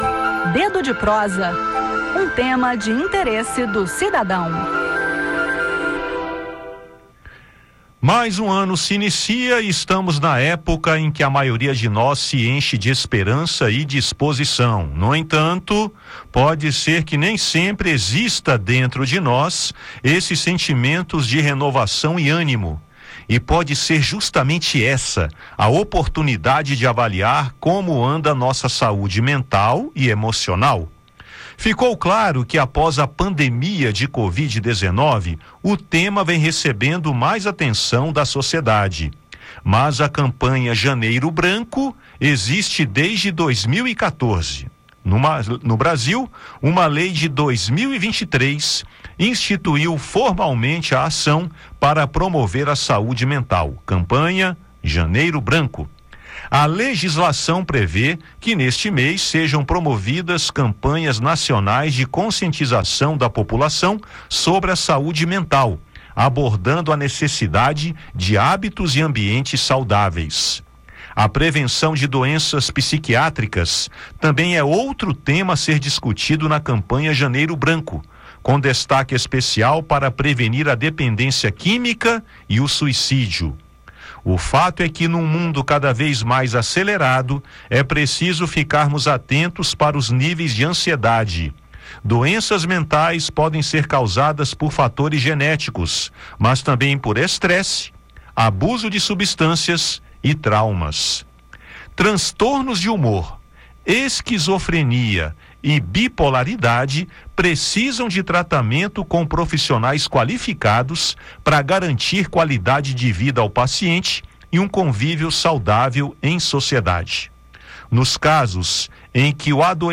No bate-papo, entenda a importância de cultivar hábitos e ambientes saudáveis e saiba como buscar ajuda, pois a saúde mental é tão importante quanto a física.